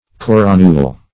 Search Result for " coronule" : The Collaborative International Dictionary of English v.0.48: Coronule \Cor"o*nule\ (k?r"?-n?l), n. [L. coronula, dim. of corona crown.]